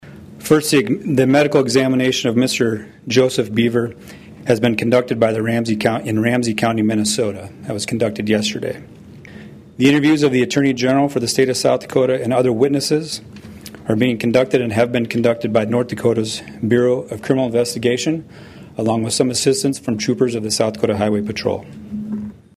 Department of Public Safety Secretary Craig Price answered questions at a news conference and says multiple agencies are involved.